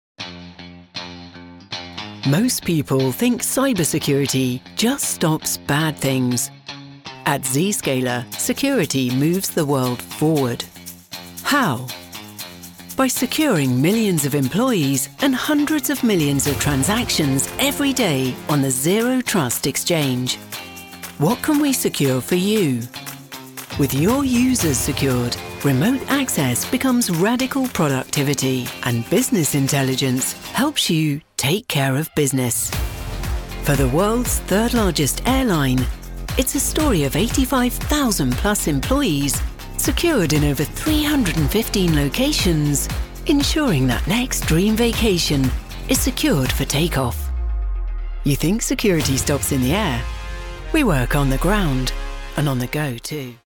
British English Female Voice Over Artist
Assured, Authoritative, Confident, Conversational, Corporate, Deep, Engaging, Friendly, Funny, Gravitas, Natural, Posh, Reassuring, Smooth, Upbeat, Versatile, Warm
Microphone: Neumann TLM 103
Audio equipment: Focusrite clarett 2 PRE, Mac, fully sound-proofed home studio